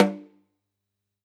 Index of /musicradar/Kit 1 - Acoustic close
CYCdh_K1close_SnrOff-08.wav